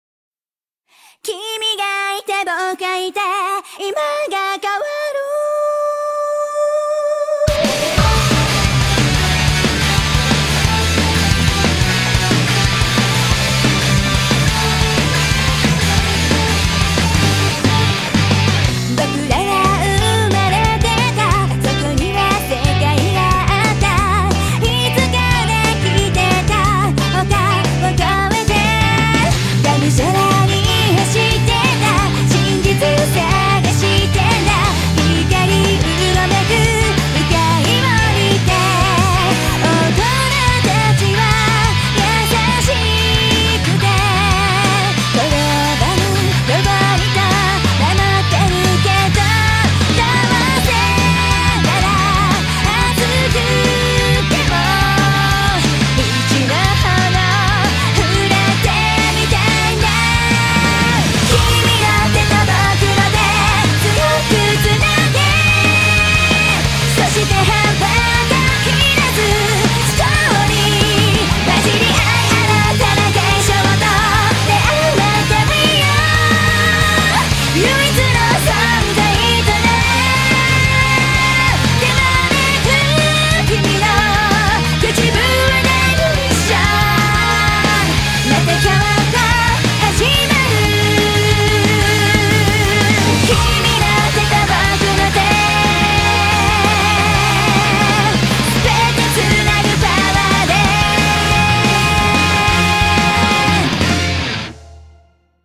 BPM90-180
Audio QualityCut From Video